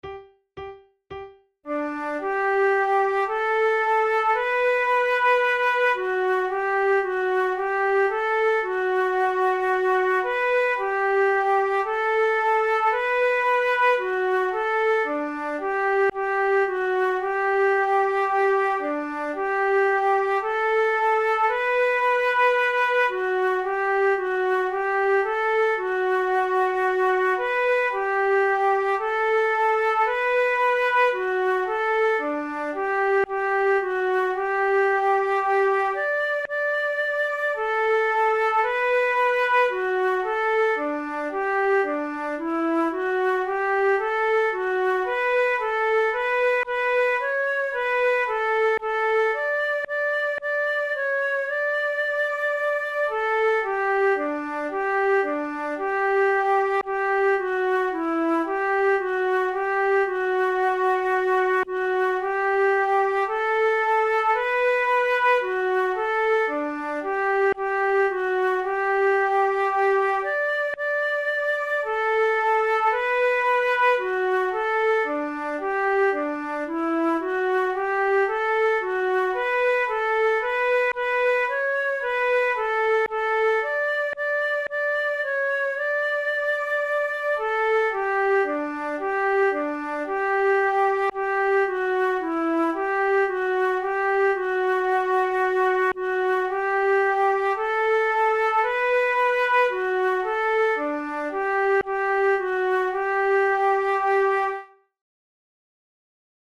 Tune of the Day: Slow March
from Handel's “Scipione”, arranged for Flute duet
Categories: Baroque Marches Opera excerpts Difficulty: easy
handel-scipione-march-1.mp3